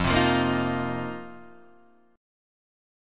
F 7th Suspended 4th